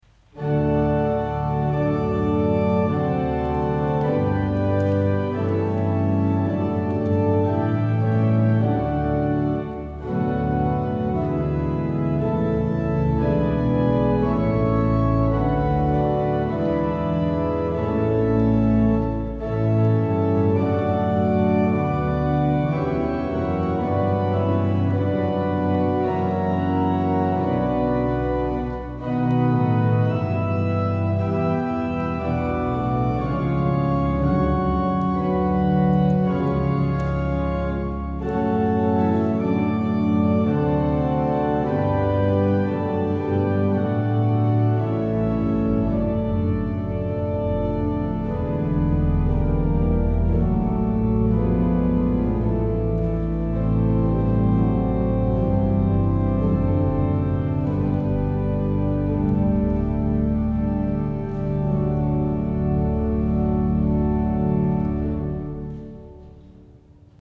à l'orgue de St Agricol d'Avignon